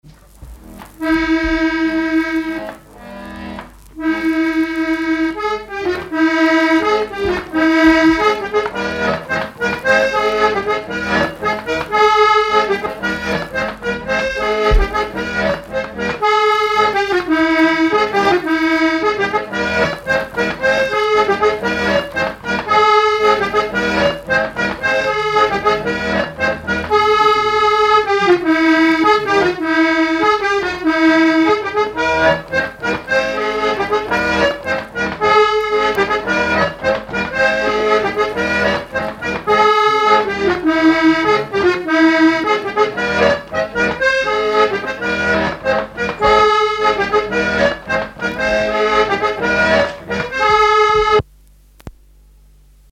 Chants brefs - A danser
à l'accordéon diatonique
Pièce musicale inédite